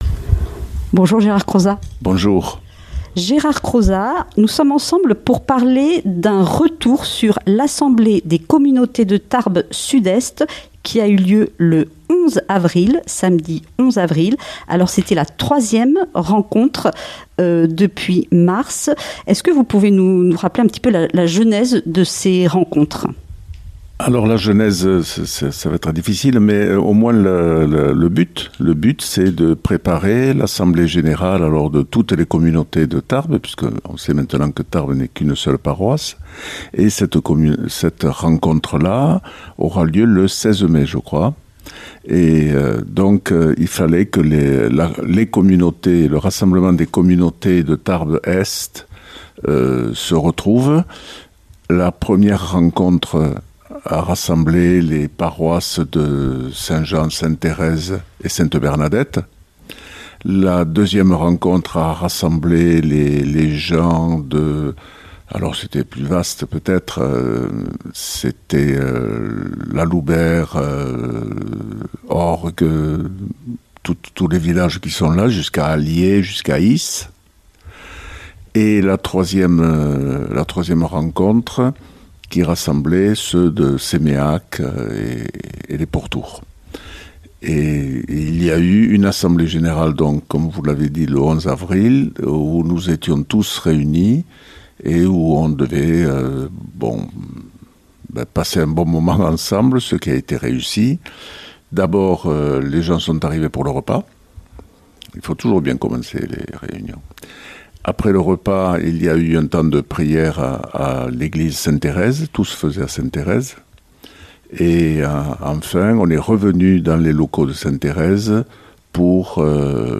Une émission présentée par
Présentatrice